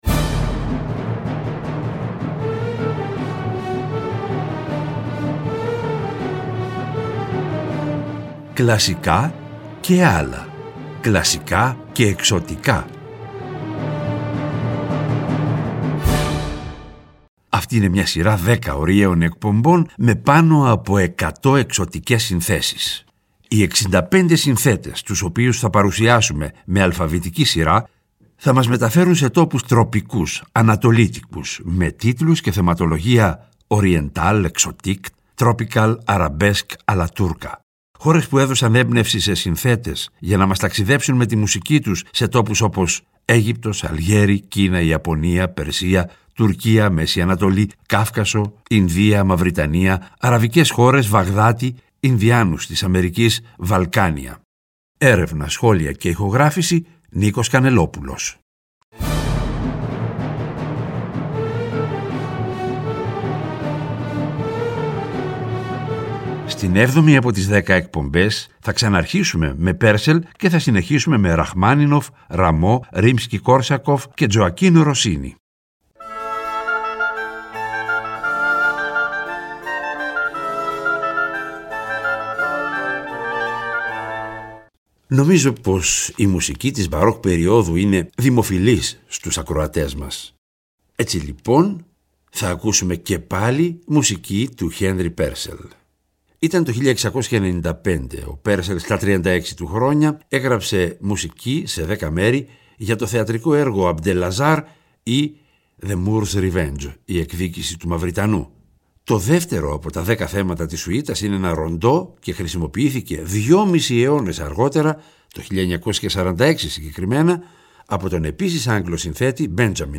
Τον Απρίλιο τα «Κλασικά και ..Άλλα» παίρνουν χρώμα Ανατολής και γίνονται «Κλασικά και …Εξωτικά», σε μια σειρά 10 ωριαίων εκπομπών, με πάνω από 100 εξωτικές συνθέσεις.
Και, προς το τέλος κάθε εκπομπής, θα ακούγονται τα… «άλλα» μουσικά είδη, όπω ς μιούζικαλ, μουσική του κινηματογράφου -κατά προτίμηση σε συμφωνική μορφή- διασκευές και συγκριτικά ακούσματα.
Κλασικη Μουσικη